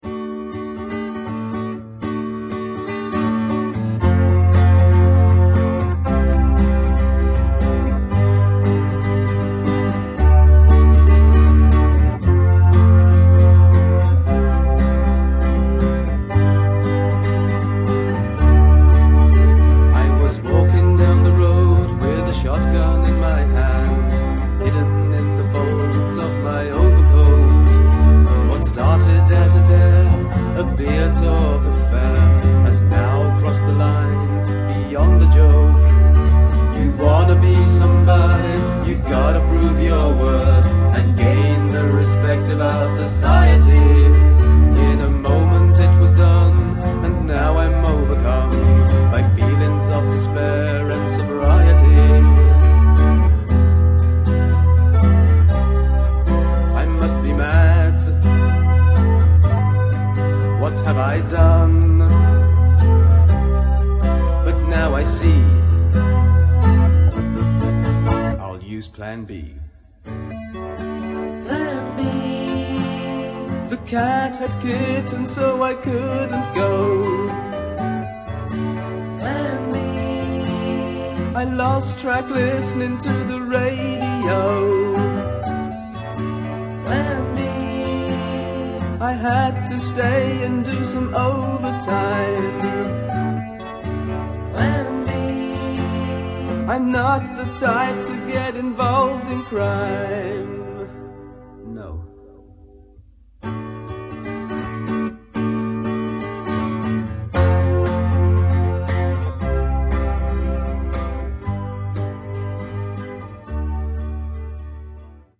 Guitar, vocals
Keyboards, vocals
Drums, vocals